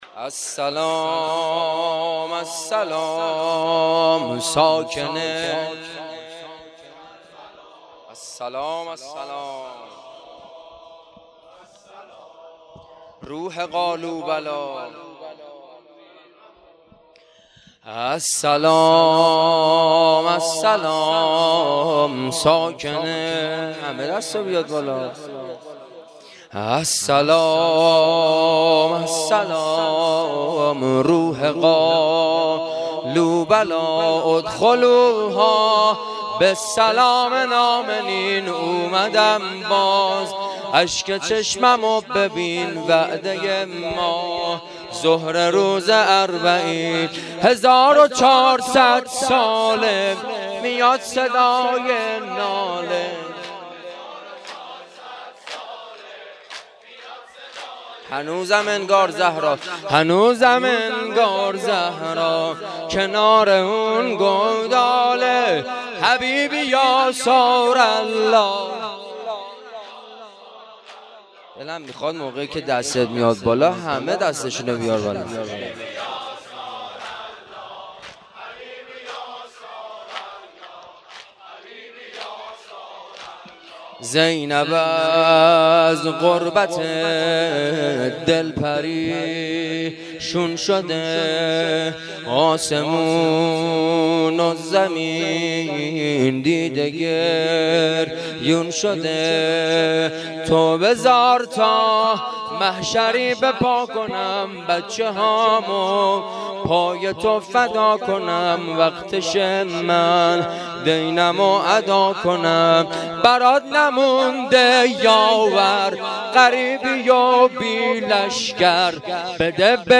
شب چهارم محرم 1440